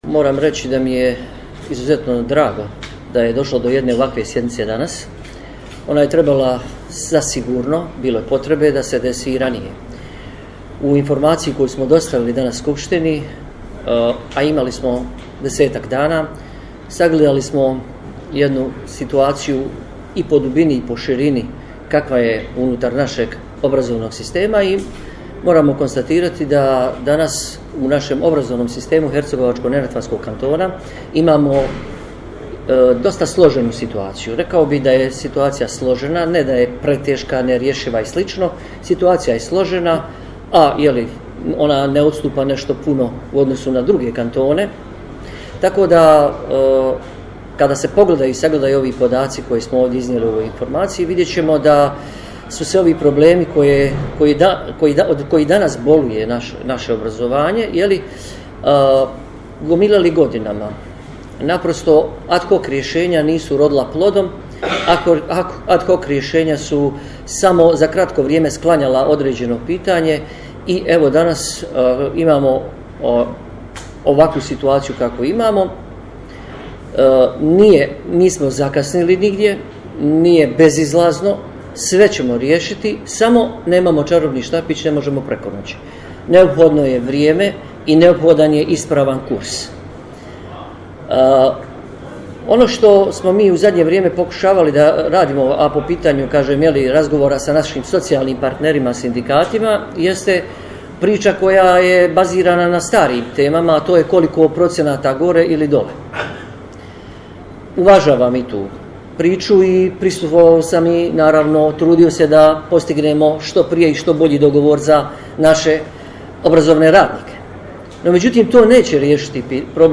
Audio snimku izjave ministra obrazovanja, nauke, kulture i sporta HNK-a Adnana Velagića, kao i audio snimka završnog izlaganja predsjednice HNK-a Marije Buhač dostupni su u prilogu.
adnan_velagic_2.mp3